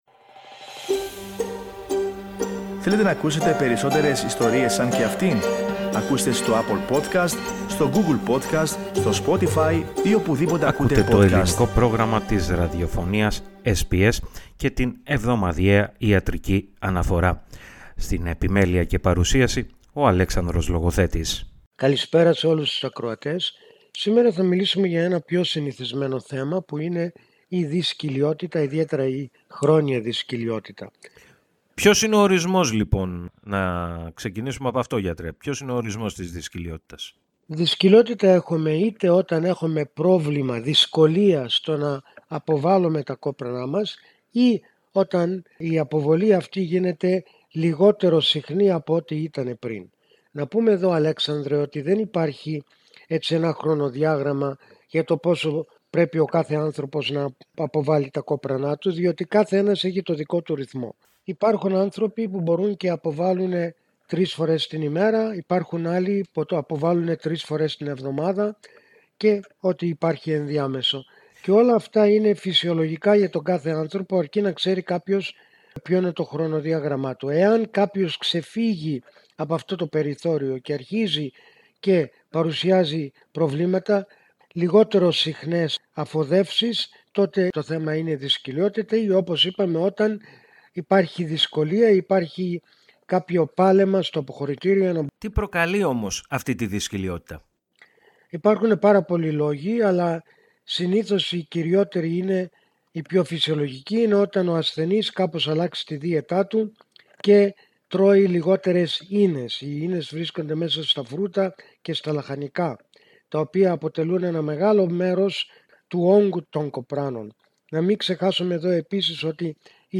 Για τους τρόπους αντιμετώπισης της δυσκοιλιότητας, και γενικότερα για την πάθηση, ακούστε ολόκληρη τη συνέντευξη, πατώντας το σύμβολο στο μέσο της κεντρικής φωοτγραφίας.